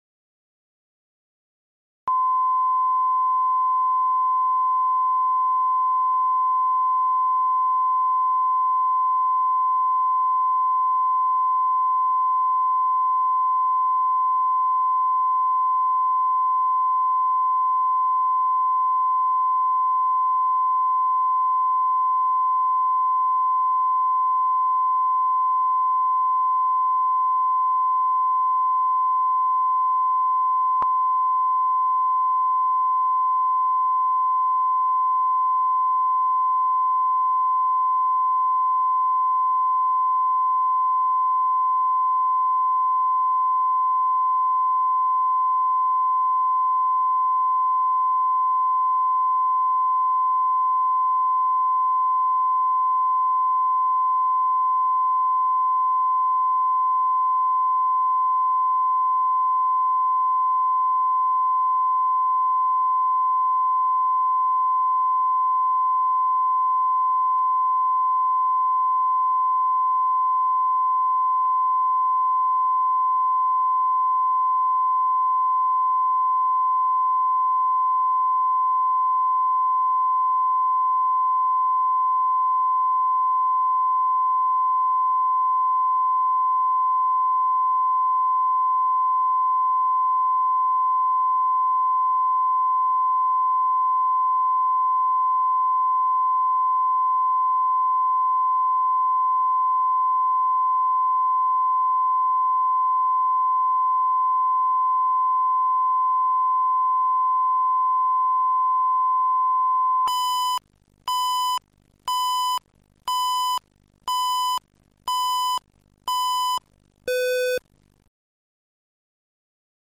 Аудиокнига Ненавижу его носки | Библиотека аудиокниг